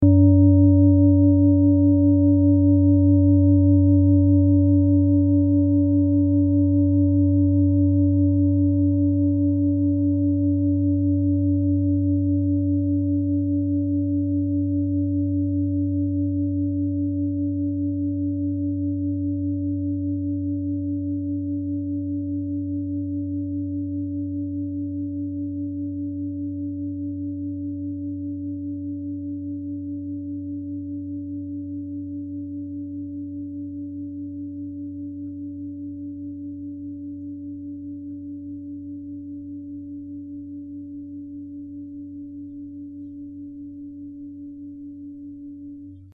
Klangschale TIBET Nr.37
Klangschale-Gewicht: 2580g
Klangschale-Durchmesser: 30,4cm
Sie ist neu und ist gezielt nach altem 7-Metalle-Rezept in Handarbeit gezogen und gehämmert worden..
(Ermittelt mit dem Filzklöppel oder Gummikernschlegel)
Die 24. Oktave dieser Frequenz liegt bei 187,61 Hz. In unserer Tonleiter liegt dieser Ton nahe beim "Fis".
klangschale-tibet-37.mp3